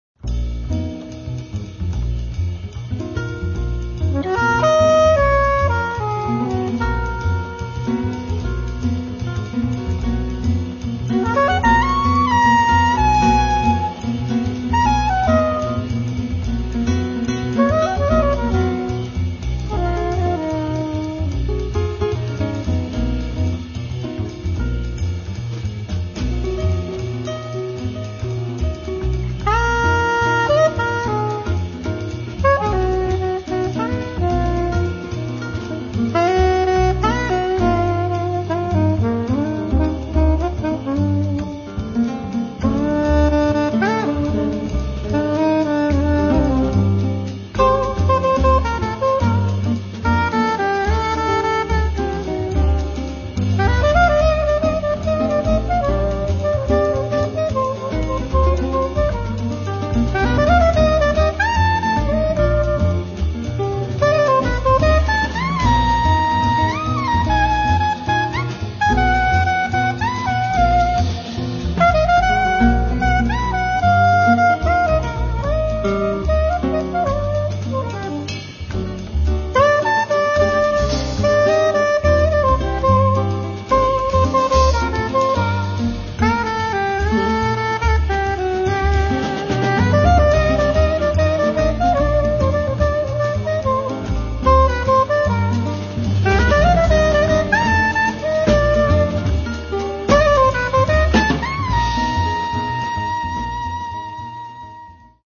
soprano and tenor sax
drums
acoustic guitar
double bass